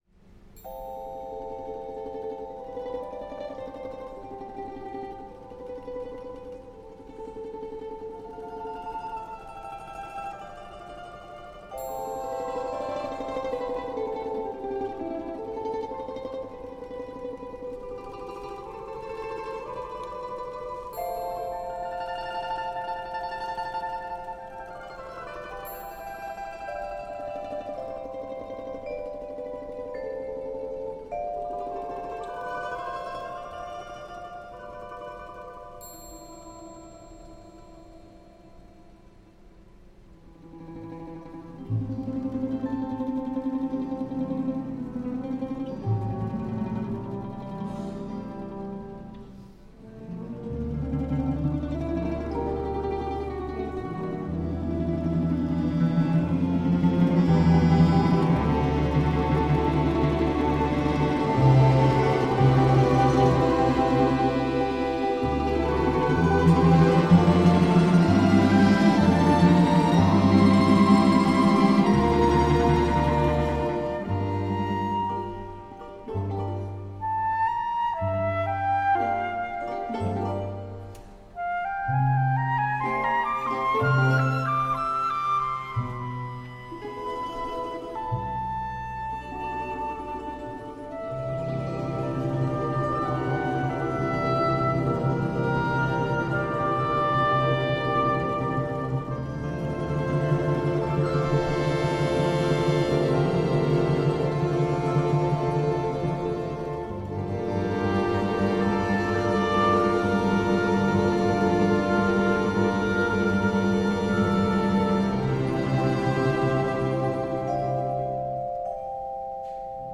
«Север – батюшко» - сочинение для оркестра народных инструментов, в котором автор постарался передать красоту и самобытность Архангельской области.